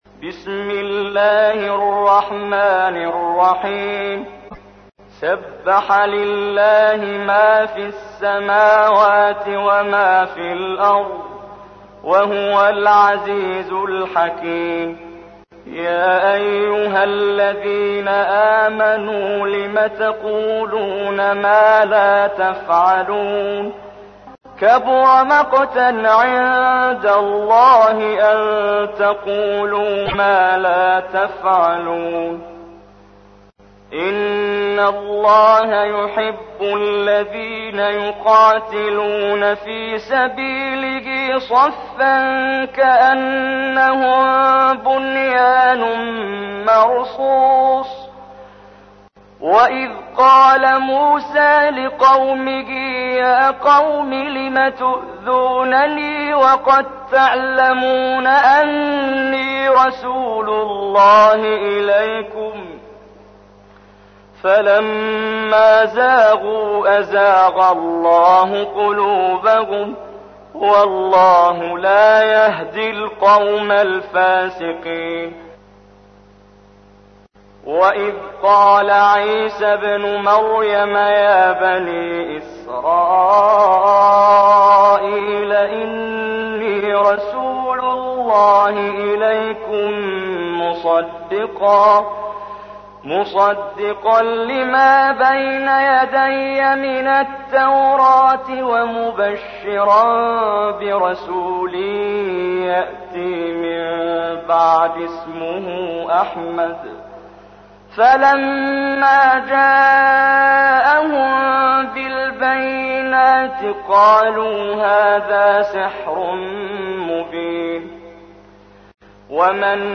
تحميل : 61. سورة الصف / القارئ محمد جبريل / القرآن الكريم / موقع يا حسين